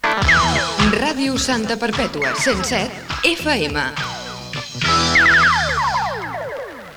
Indicatiu de l'emissora
FM